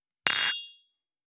269,ショットグラス乾杯,乾杯,アルコール,バー,お洒落,モダン,カクテルグラス,ショットグラス,おちょこ,テキーラ,シャンパングラス,カチン,
コップ